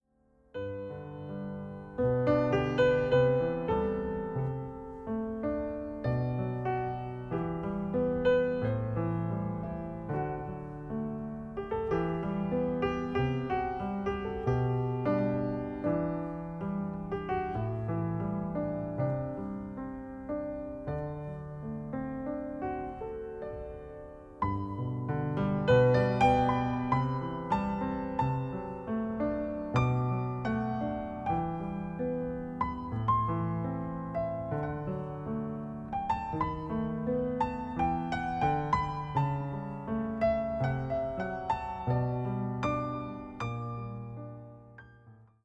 ジャズ作品としては稀有なほどの静謐さがそこにありながら、突き放すような冷酷さは無く、メロディには親しみを感じさせる。
Piano
Double Bass
Percussion